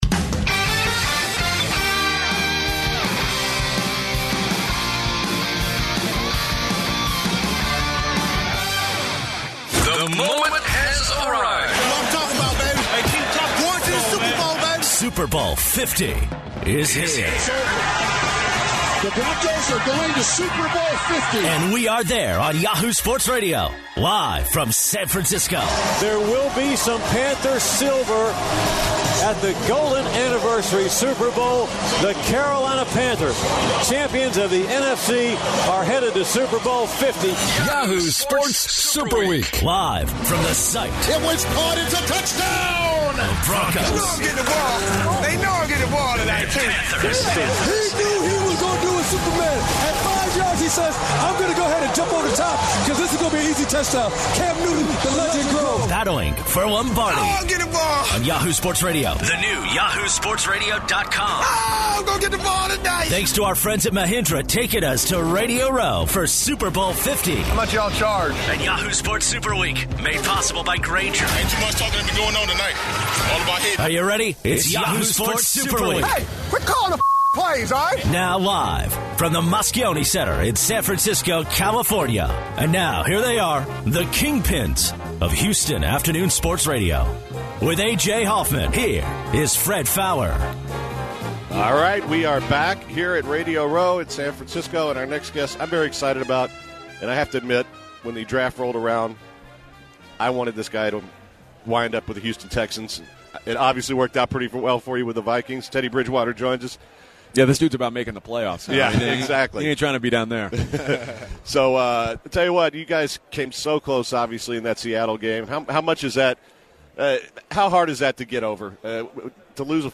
The guys started this hour off by talking with Teddy Bridgewater.